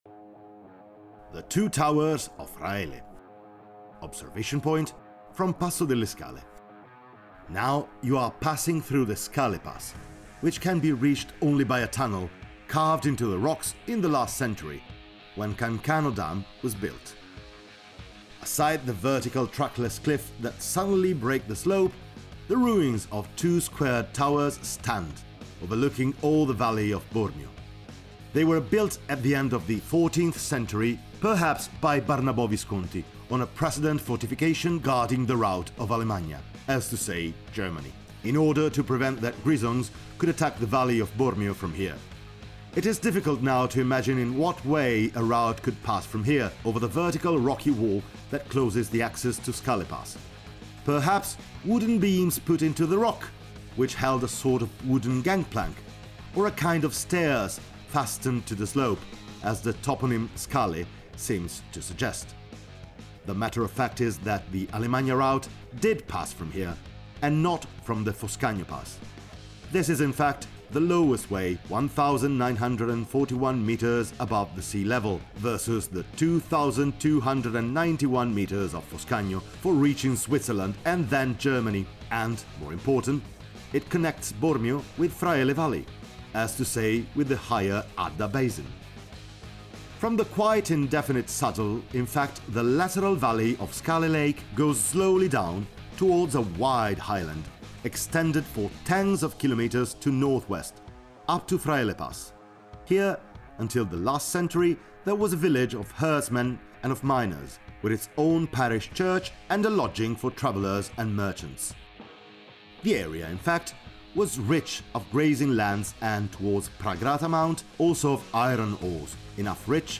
AUDIO GUIDES